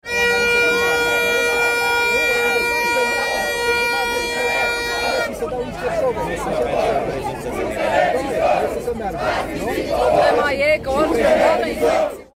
Participanții la protestul din Piața Victoriei au scandat „Libertate” şi „Jos Guvernul”.
13iul-07-ambianta-proteste-anti-covidisti.mp3